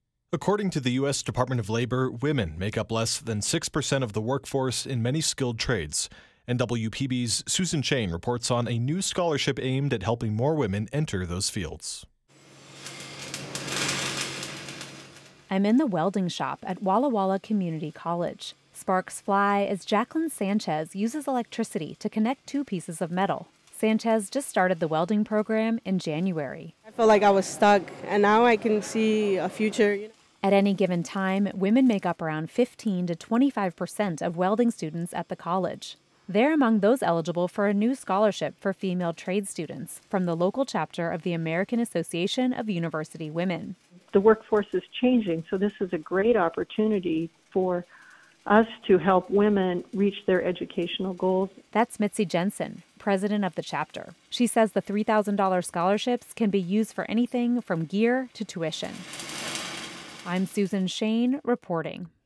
A welder in a helmet connects two pieces of metal as sparks fly.